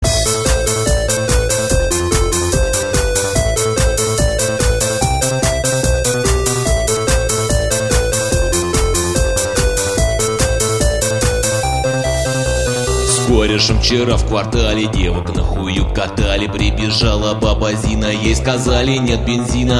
• Качество: 320, Stereo
electro